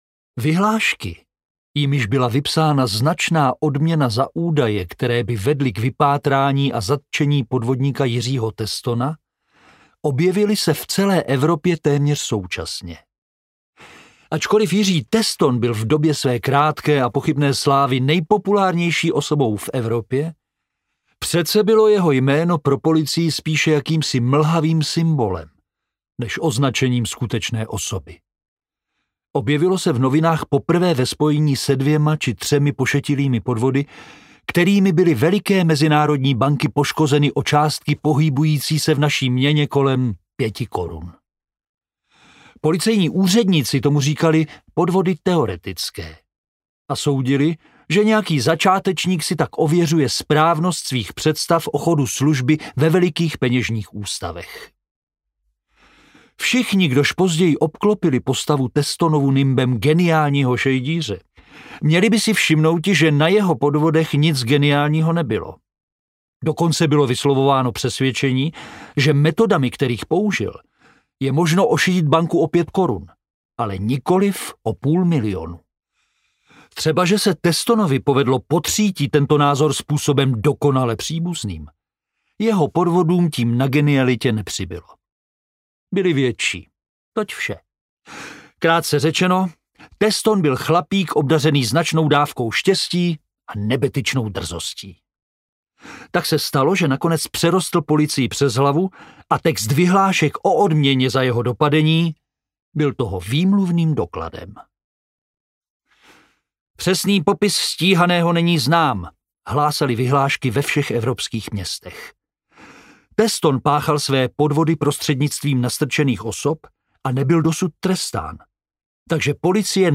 Muž se psem audiokniha
Ukázka z knihy
Čte Jaromír Dulava.
Vyrobilo studio Soundguru.